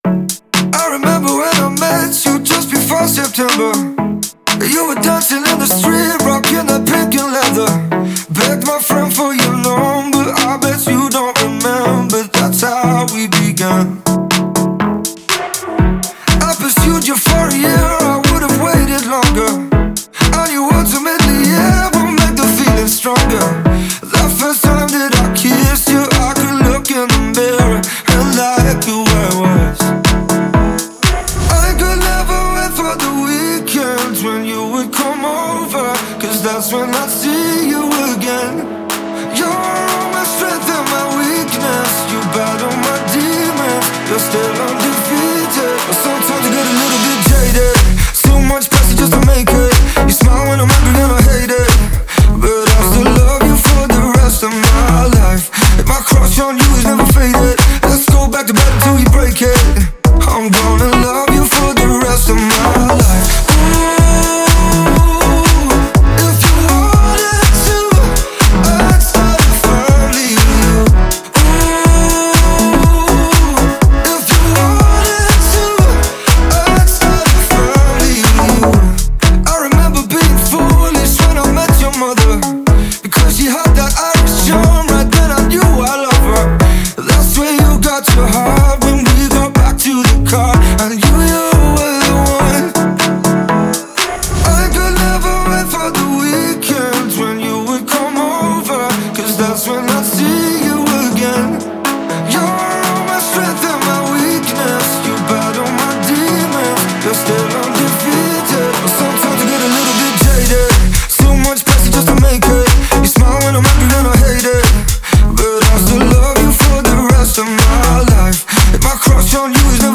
это энергичный трек в жанре поп и EDM